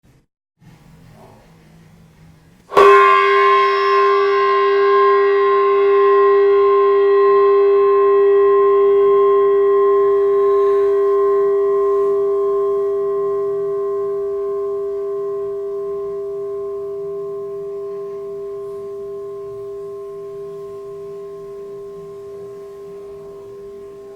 Meditation Gong Sound Effect Free Download
Meditation Gong